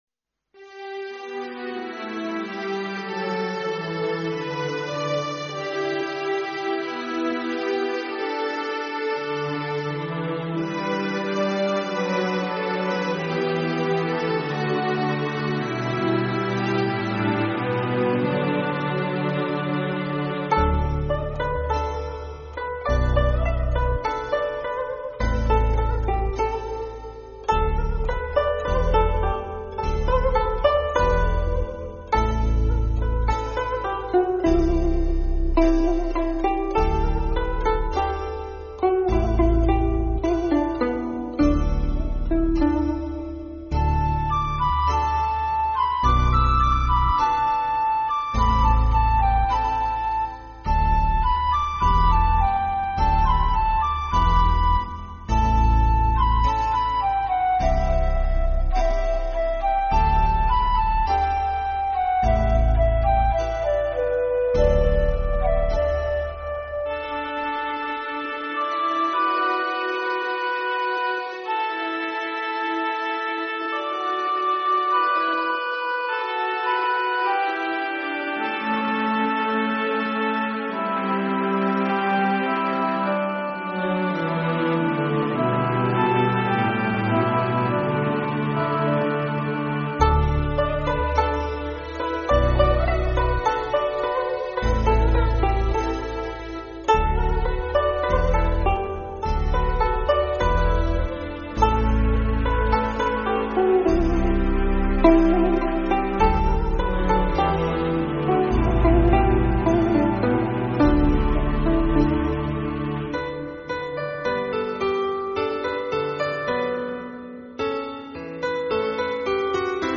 超尘脱俗的民间乐曲演奏专辑； 改变传统音乐既有形象、赋予民乐全新风貌，是写景写意的杰出作品。